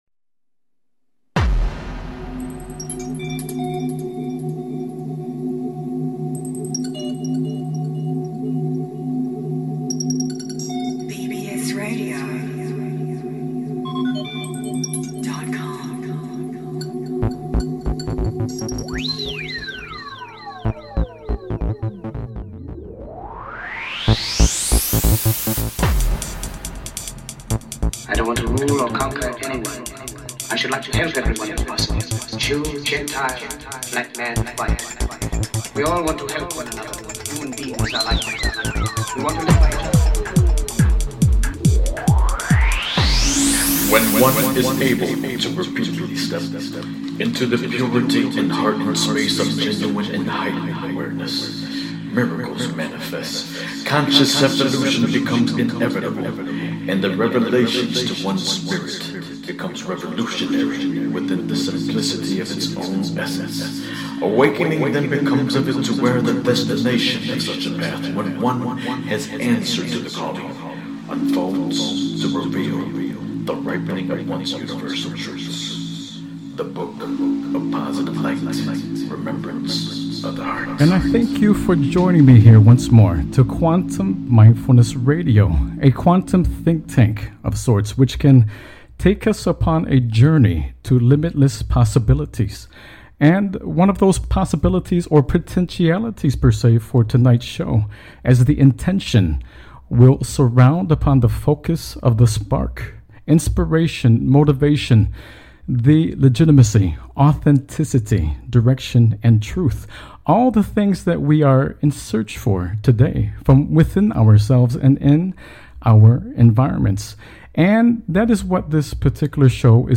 Headlined Show, Quantum Mindfulness Radio October 27, 2014